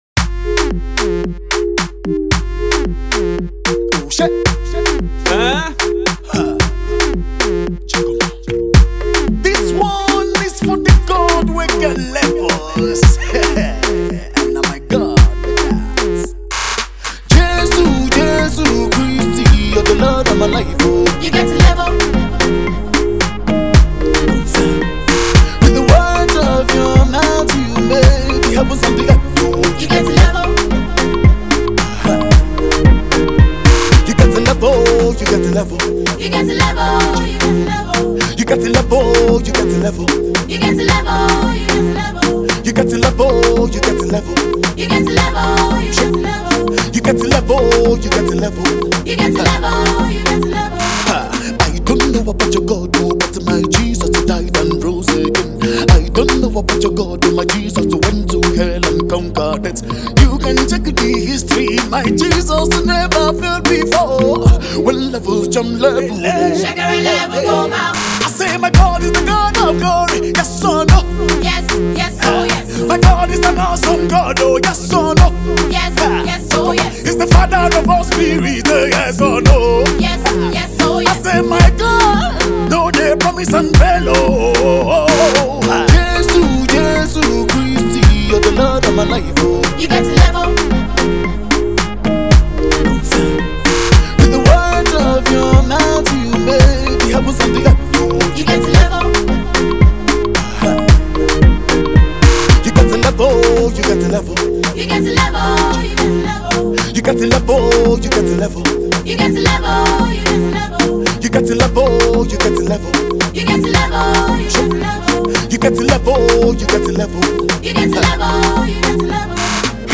the thrilling Afro Gospel-music minister